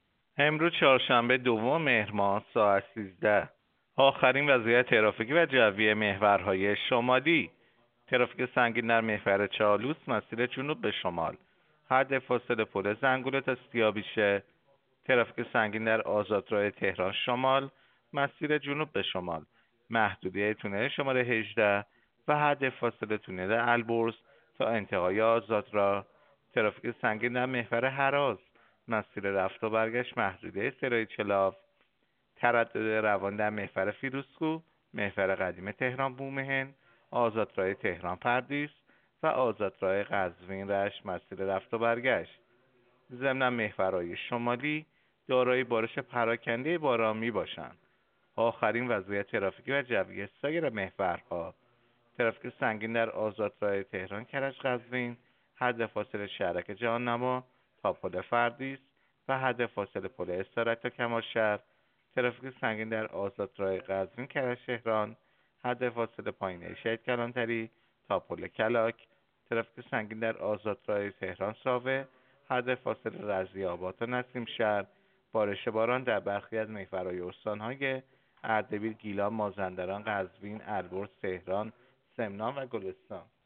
گزارش رادیو اینترنتی از آخرین وضعیت ترافیکی جاده‌ها ساعت ۱۳ دوم مهر؛